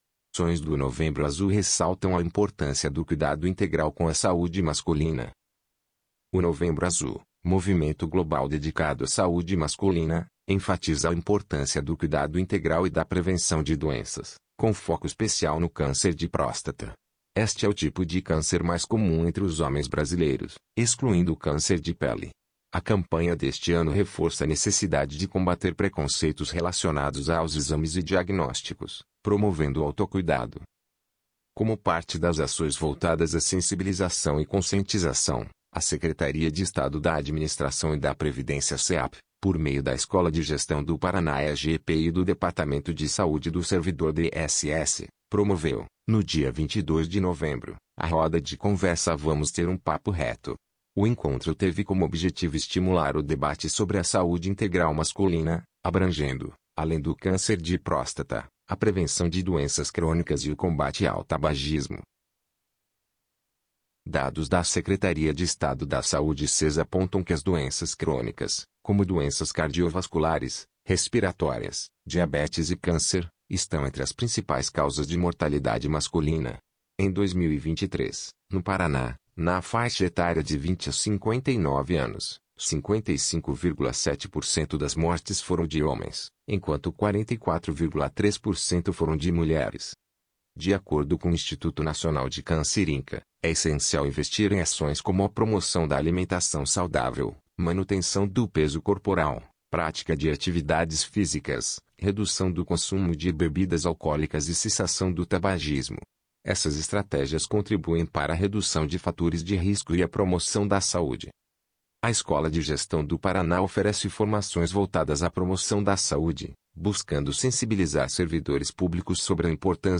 audionoticia_acoes_do_novembro_azul_ressaltam.mp3